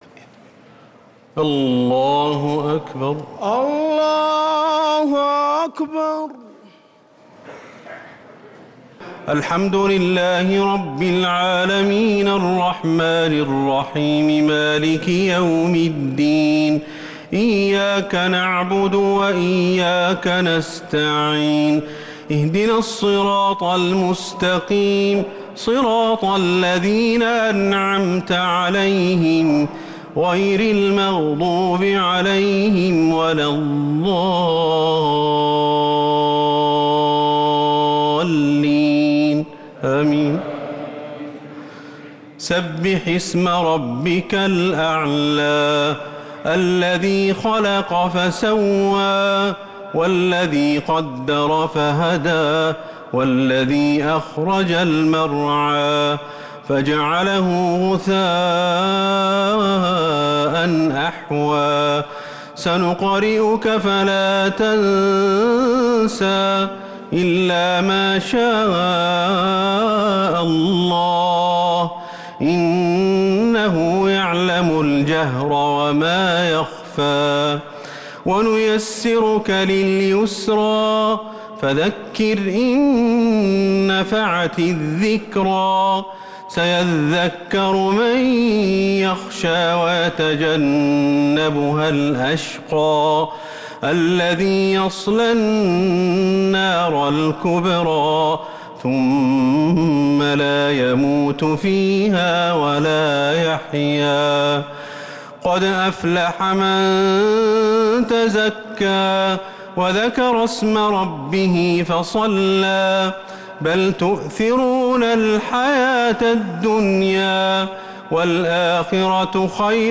صلاة الشفع و الوتر ليلة 28 رمضان 1446هـ | Witr 28th night Ramadan 1446H > تراويح الحرم النبوي عام 1446 🕌 > التراويح - تلاوات الحرمين